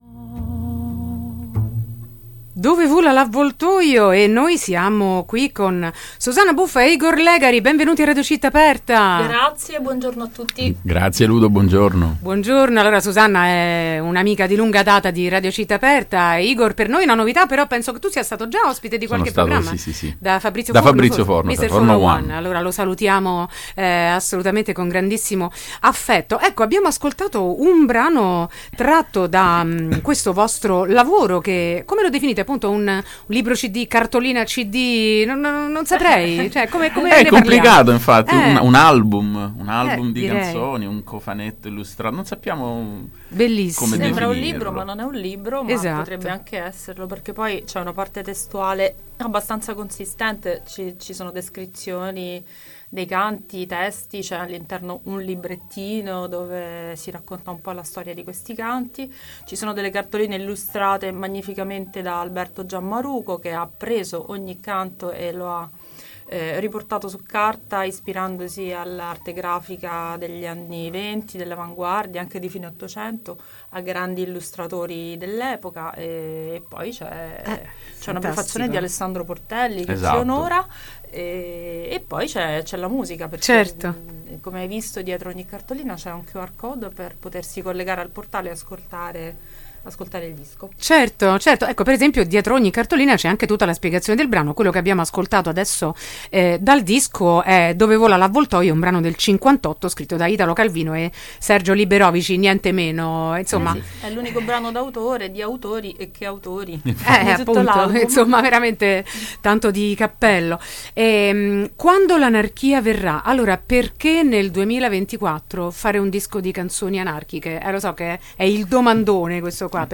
intervista e minilive
Undici canti anarchici del XIX e XX secolo